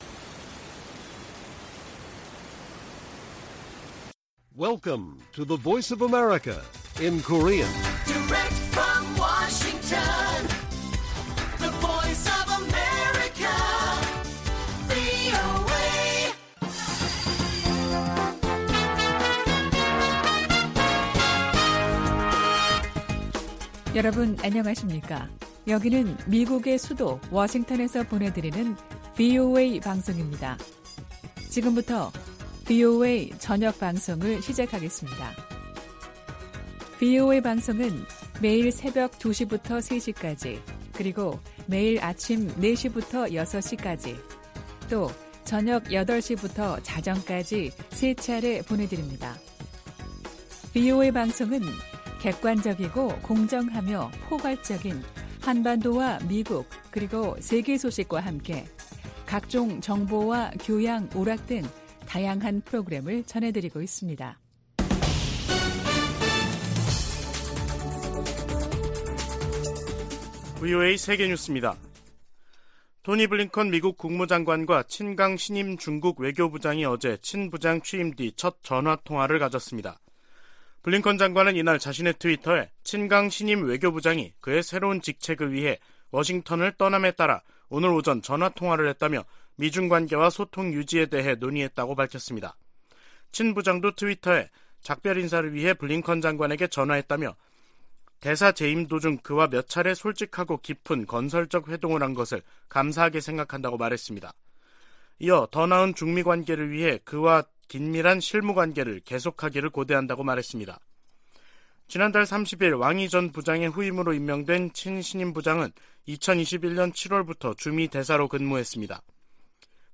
VOA 한국어 간판 뉴스 프로그램 '뉴스 투데이', 2023년 1월 2일 1부 방송입니다. 미국 국무부가 이틀 연속 탄도미사일을 발사한 북한을 비판했습니다.